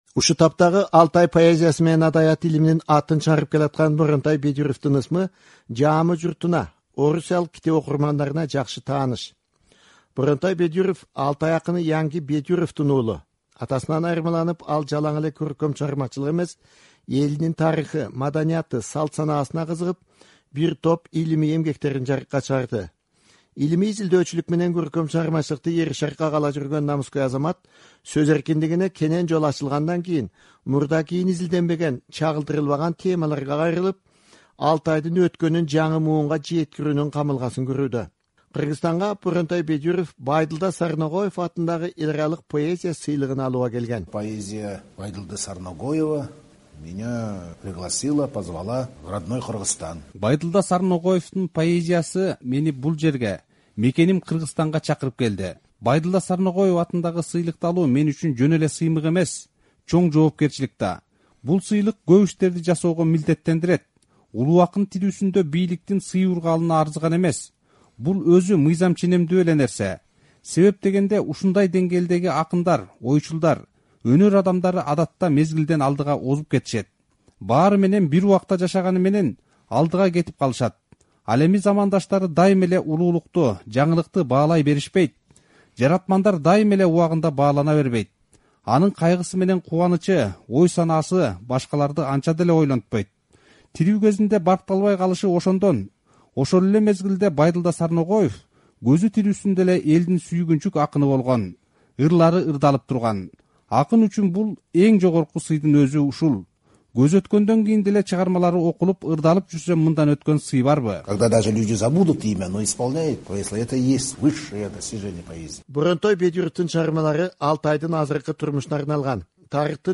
Алтай элдеринин оозеки чыгармачылыгынын үлгүлөрүн чогултуп, улуттук уңгунун түпкү сырларына кызыгып келаткан илимпоз акын "Азаттыкка" маек курду.